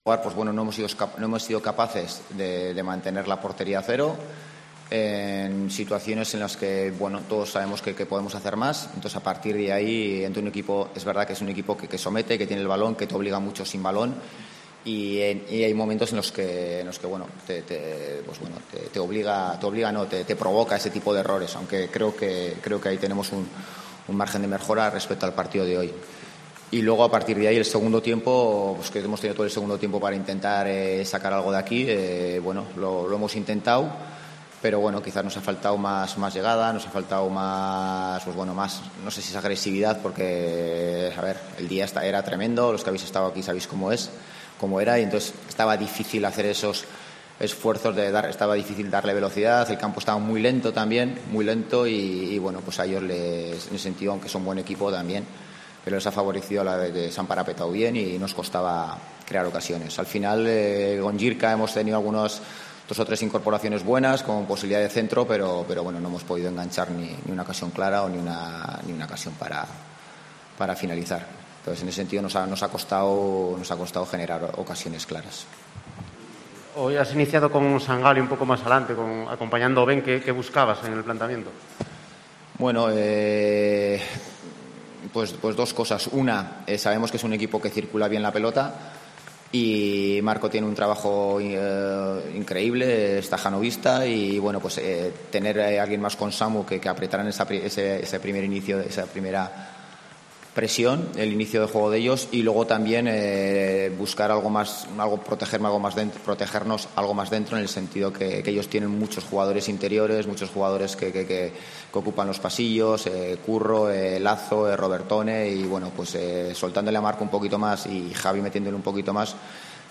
Rueda de prensa Ziganda (Almería-Oviedo)